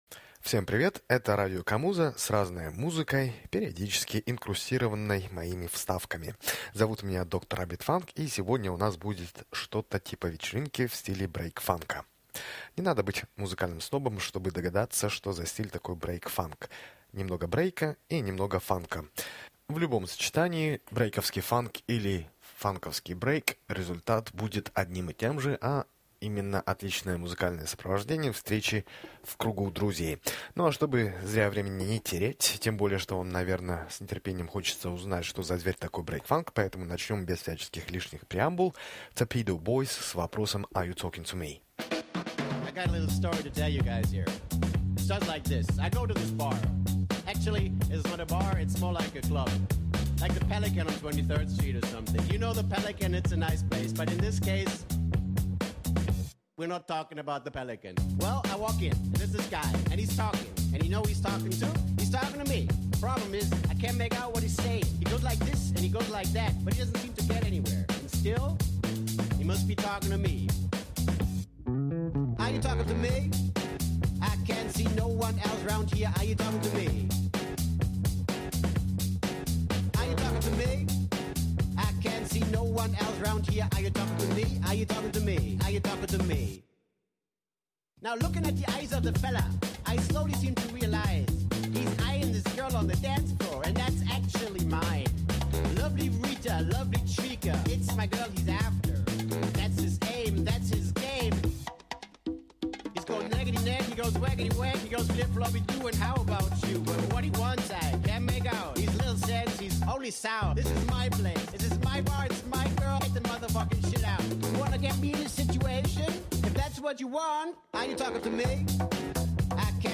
брейк-фанк
музыка для вечеринки